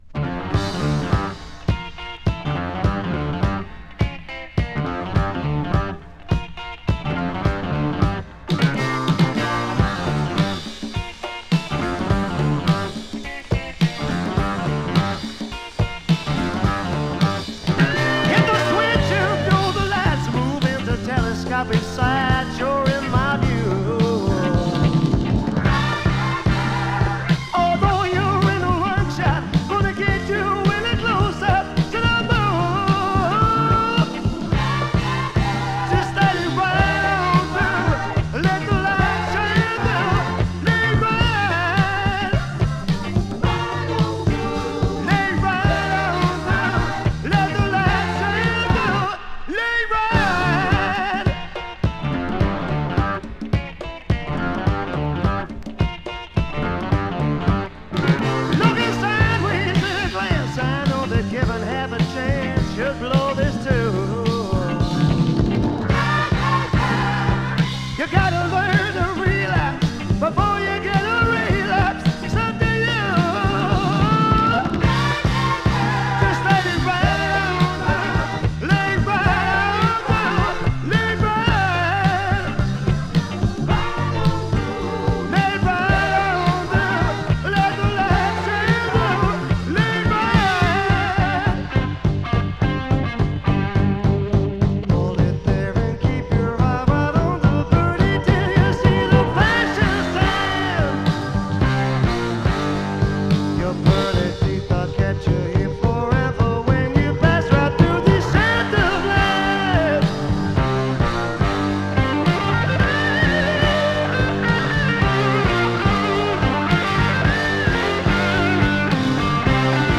Norwegian Psyche & Heavy Rock!ノルウェー産ハードロック・グループ。
北欧独特な土着的なサウンドが印象的な一枚です！
【HARD ROCK】【BLUES ROCK】